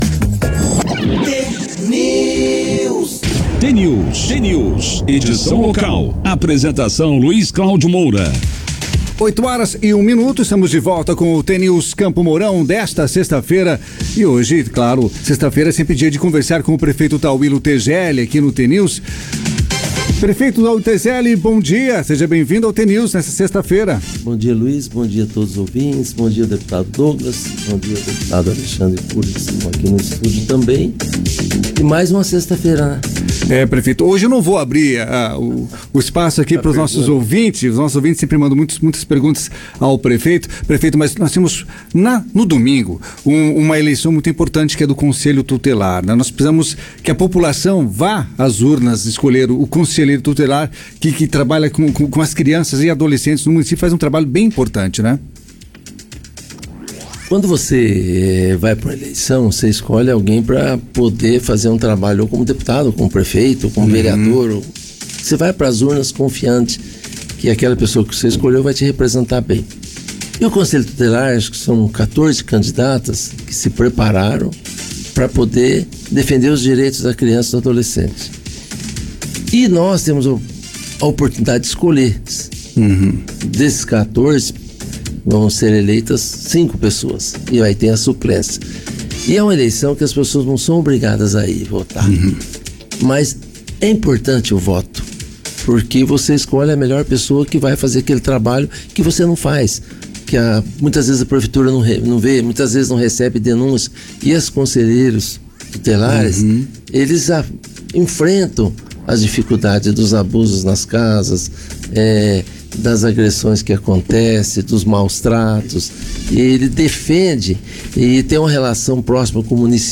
Prefeito Tauillo na Rádio T FM: confira entrevista desta 6ª feira (29/9)
Nesta sexta-feira, dia 29, como faz já há mais de duas décadas, Tauillo Tezelli, atual prefeito de Campo Mourão, marcou presença no jornal T News, da Rádio T FM.